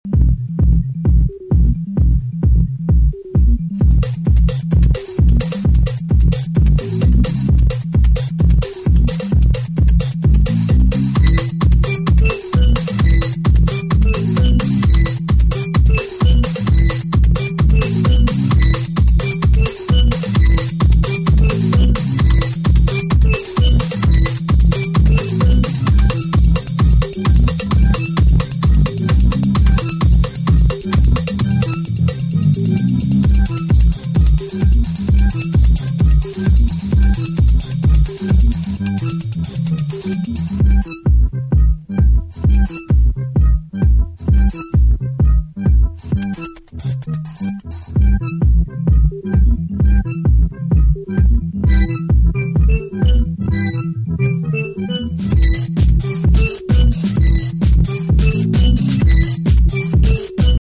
ホーム > HOUSE/BROKEN BEAT > V.A